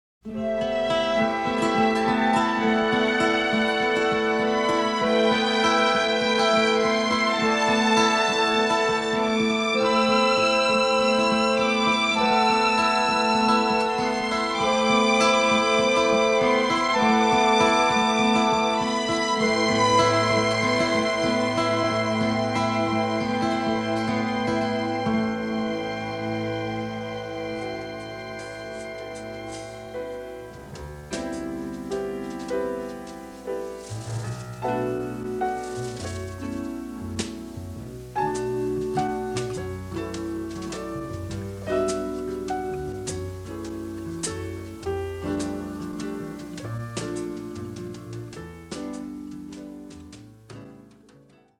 is a somber score for a small ensemble